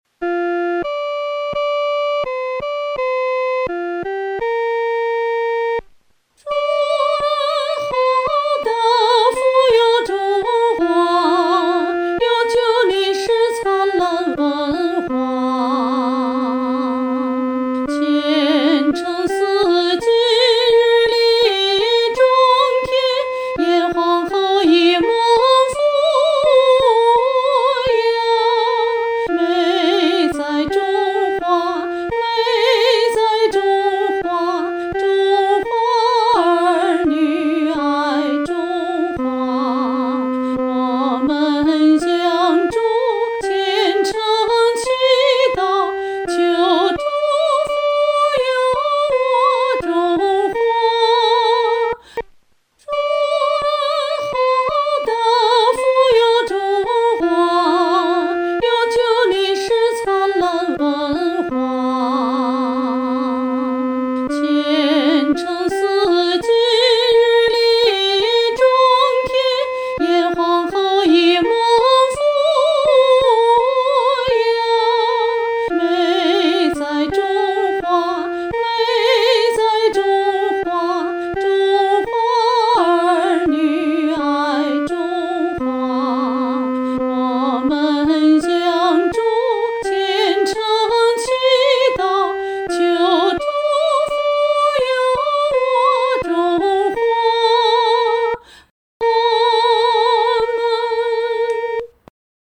合唱
四声 下载
全曲充满高亢的激情，却又行进庄严。